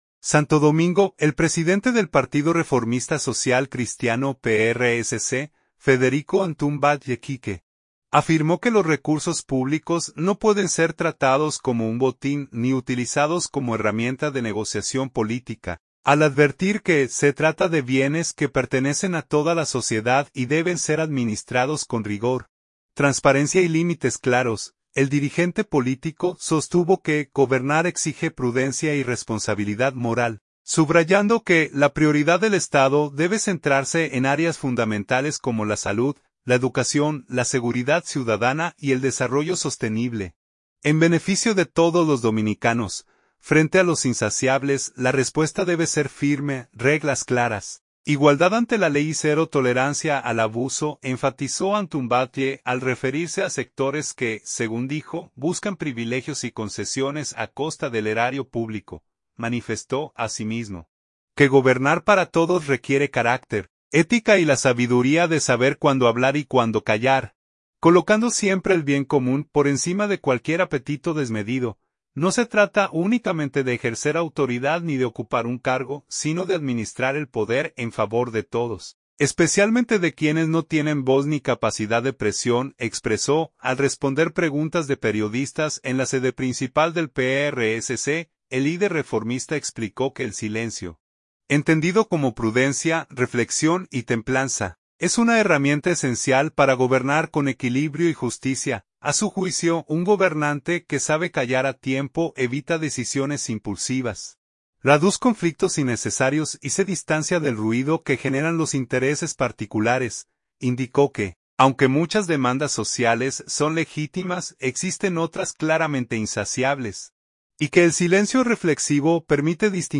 Al responder preguntas de periodistas en la sede principal del PRSC, el líder reformista explicó que el silencio, entendido como prudencia, reflexión y templanza, es una herramienta esencial para gobernar con equilibrio y justicia.